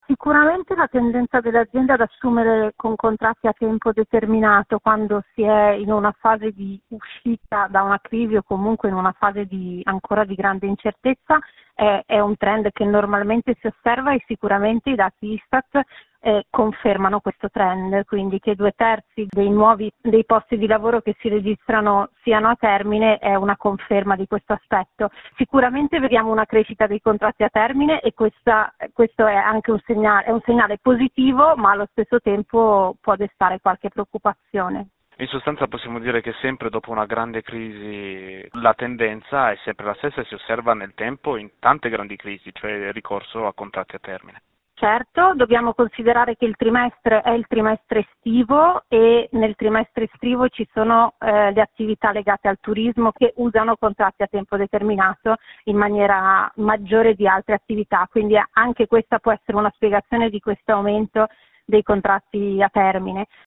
Il racconto della giornata di lunedì 13 settembre 2021 con le notizie principali del giornale radio delle 19.30. È iniziato oggi per 4 milioni di studenti il terzo anno scolastico nell’epoca del COVID-19 con orari scaglionati, mascherine, disinfettante e distanziamento.